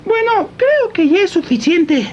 Worms speechbanks
Ohdear.wav